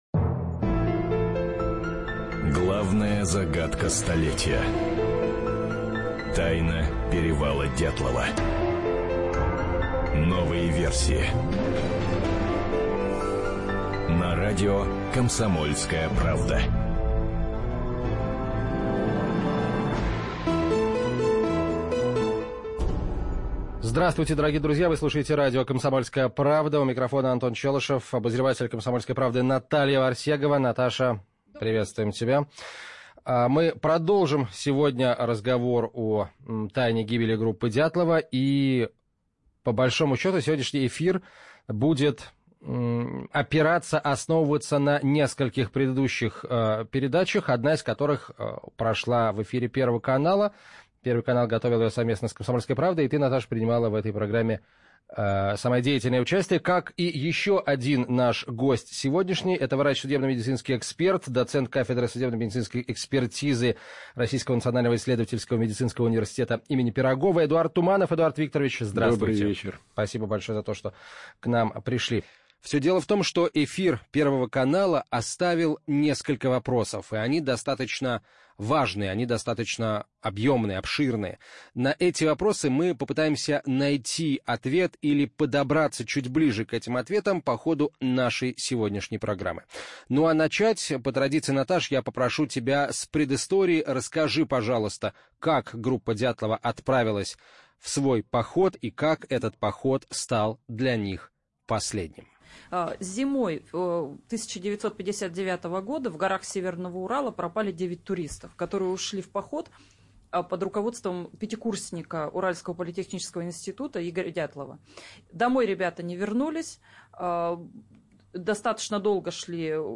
Аудиокнига Кто он?